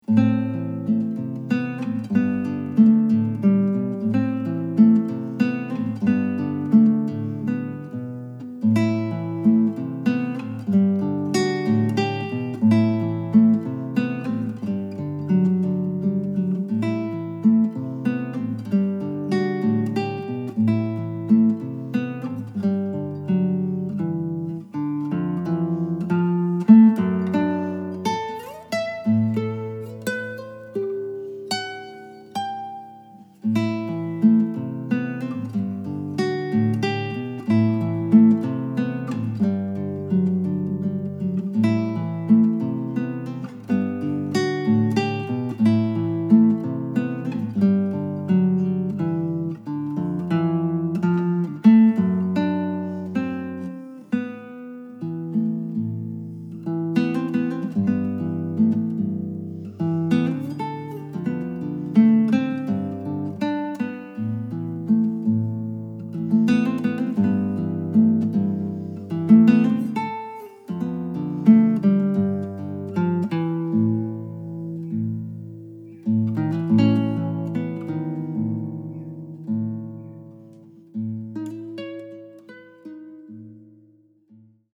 this album of solo guitar pieces is great for all occasions.
A variety of guitars were used during the performances.